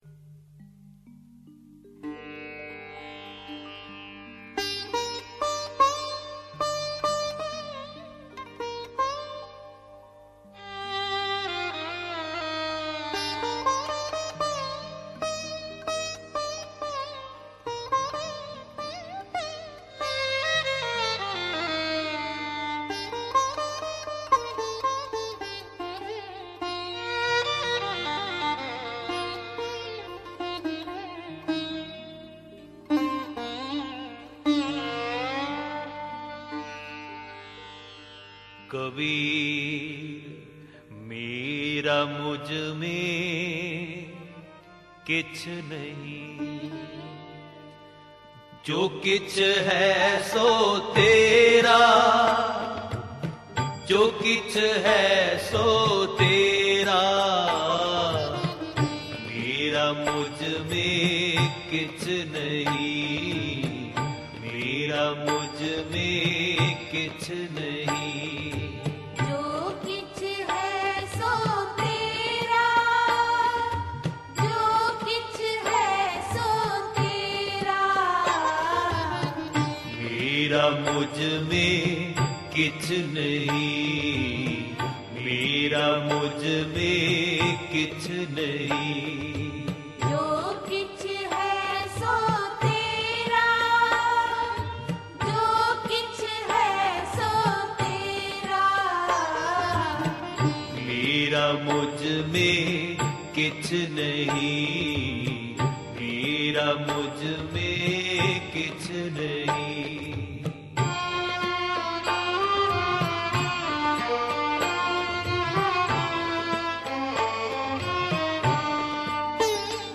Genre: Gurmat Vichar